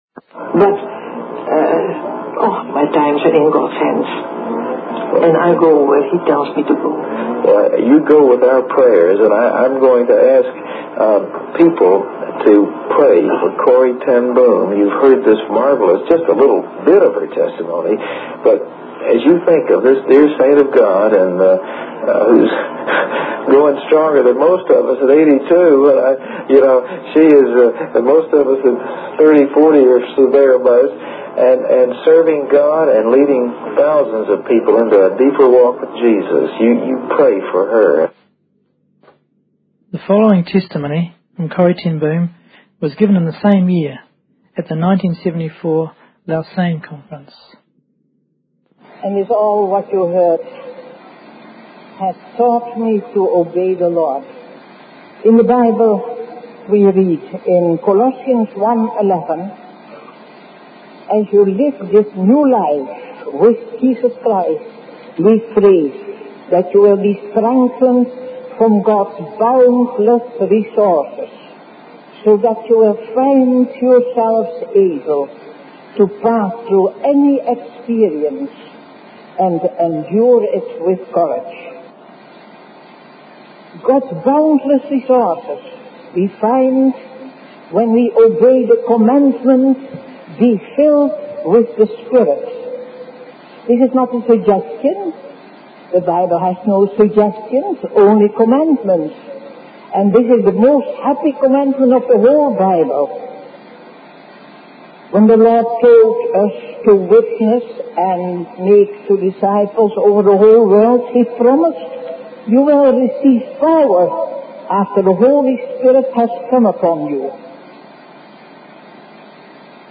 In this sermon, the speaker emphasizes the importance of relying on the power of the Holy Spirit. He shares stories to illustrate how the Holy Spirit can provide comfort and strength in times of fear and darkness.